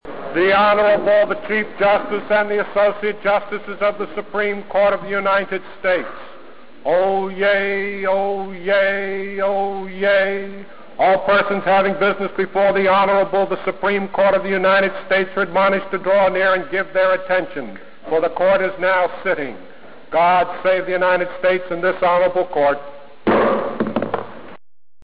supreme-court-oyez.mp3